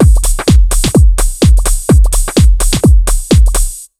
127BEAT6 7-L.wav